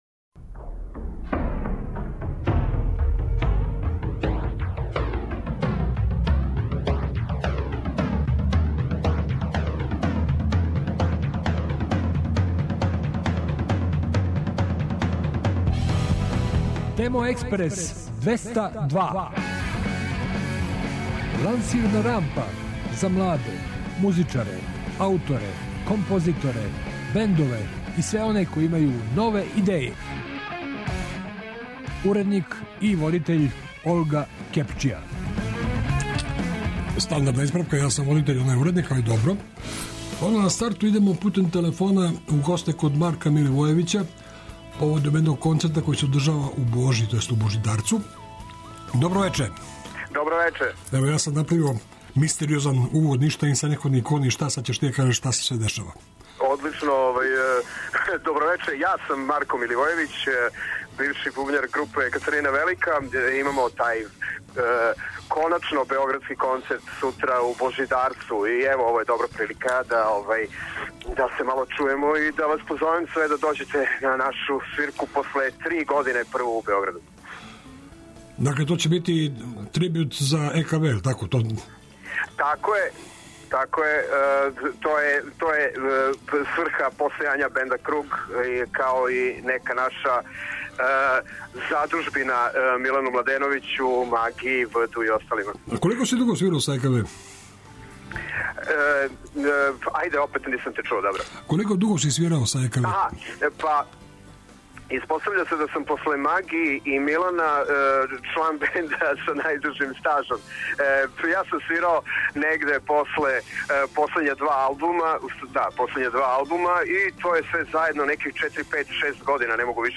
И у овонедељној емисији вас очекуjу сјајни гости, много нове музике, интересантни нови бендови и издања.